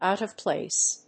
アクセントòut of pláce